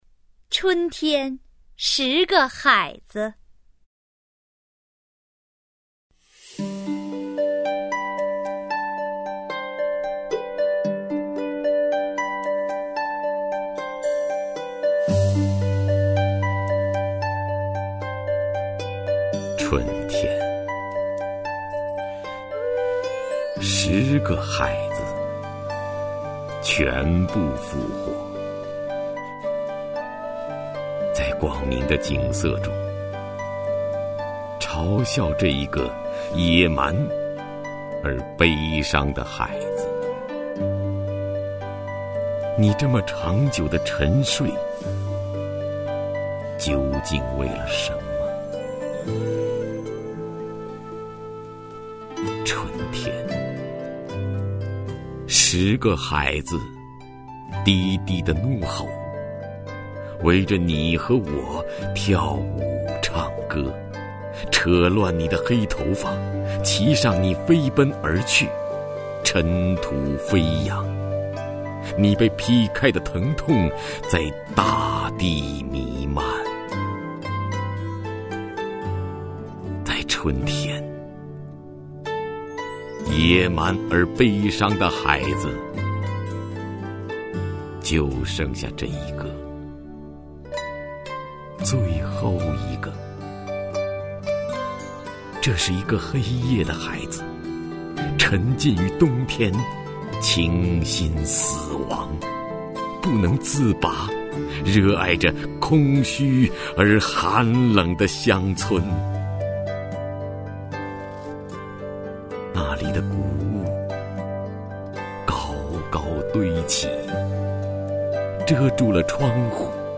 首页 视听 名家朗诵欣赏 徐涛
徐涛朗诵：《春天，十个海子》(海子)